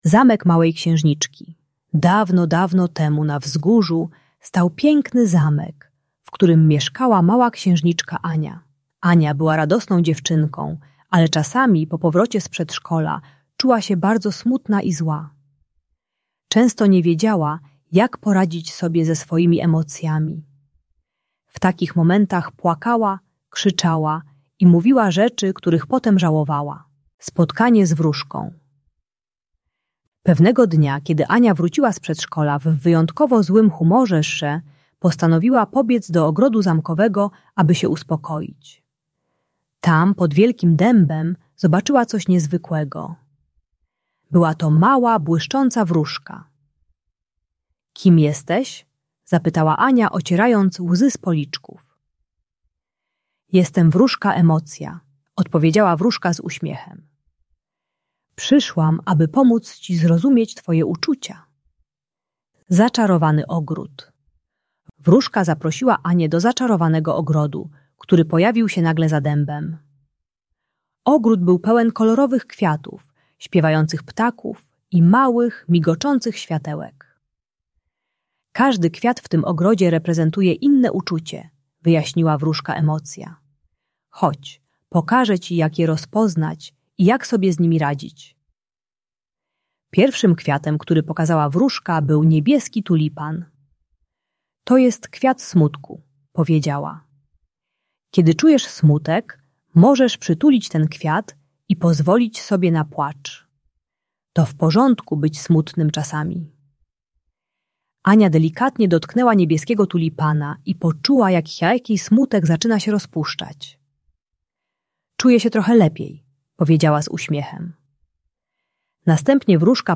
Zamek Małej Księżniczki - Bunt i wybuchy złości | Audiobajka
Uczy techniki głębokiego oddechu (dmuchania) na uspokojenie złości oraz nazywania emocji. Audiobajka o radzeniu sobie ze złością i smutkiem dla przedszkolaka.